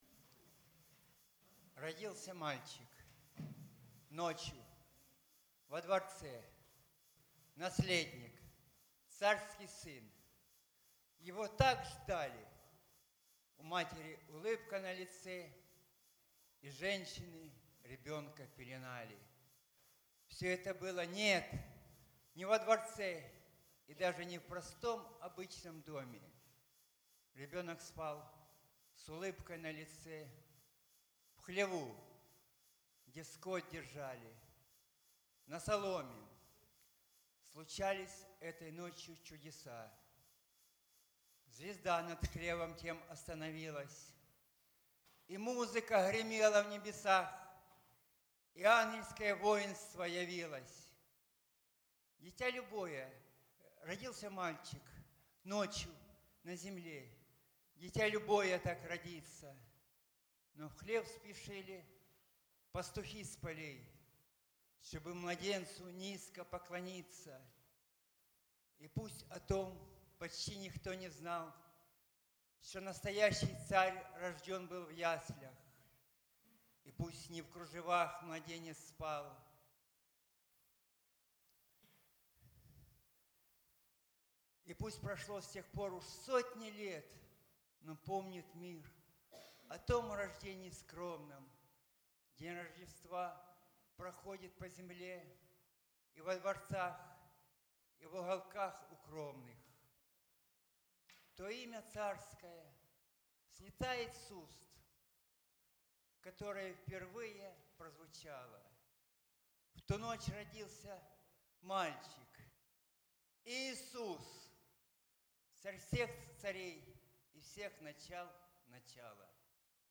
Вечернее служение 01.01.15
Стихотворение
Стих_bip.mp3